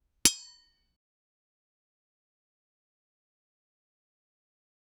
geting-hit-by-a-blade-bwlprl4l.wav